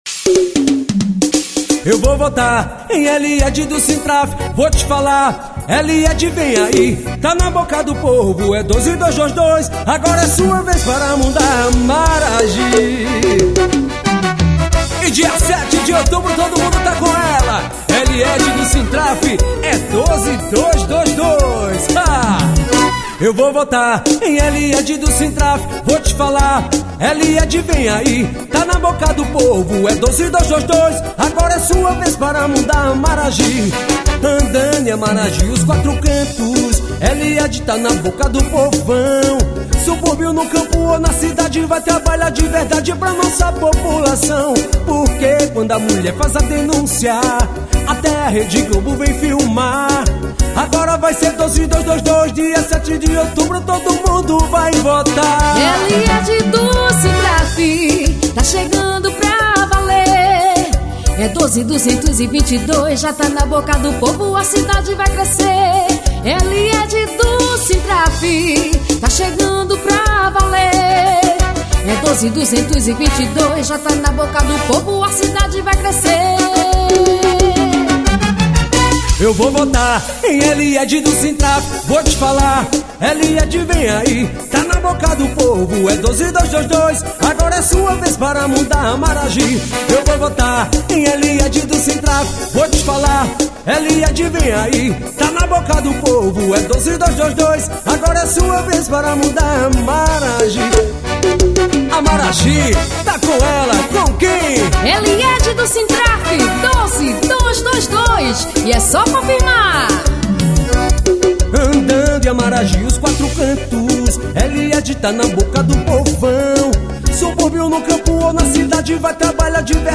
Paródias Políticas.